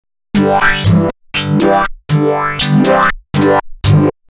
オーディオ・デモ（自作です）
FX
価格からは信じられないくらい、リアルな音色です。